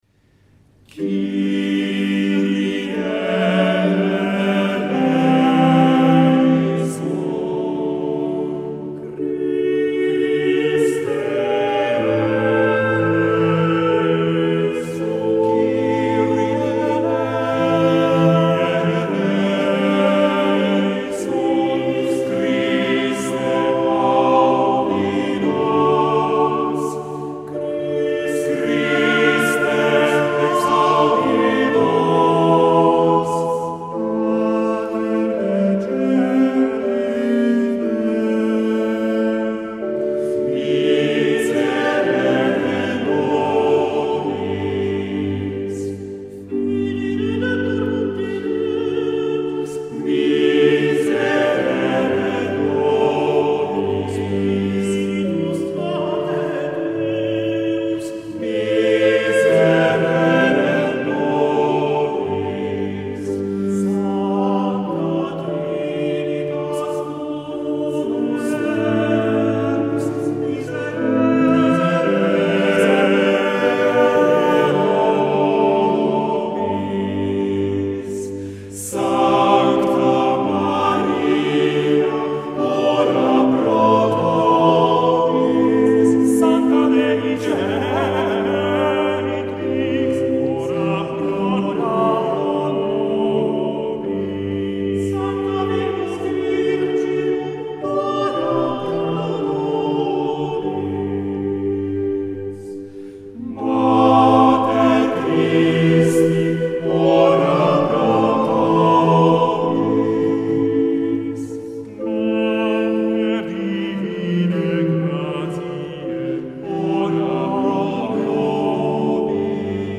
Concertato